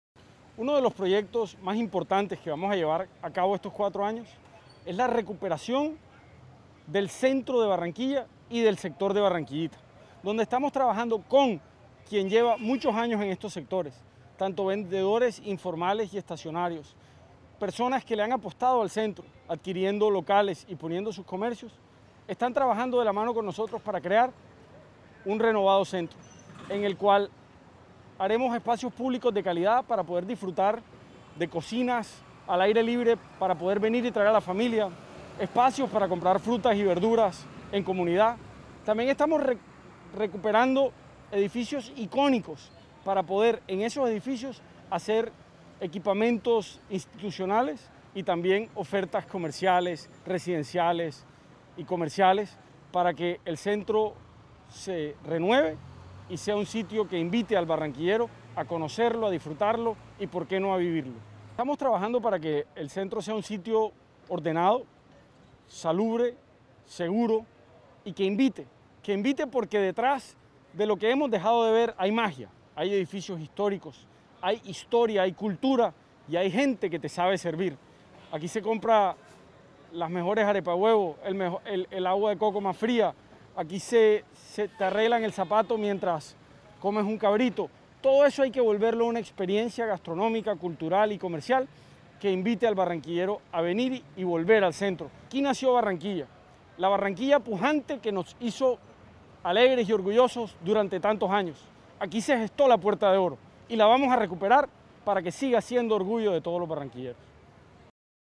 Audio del alcalde distrital, Jaime Pumarejo Heins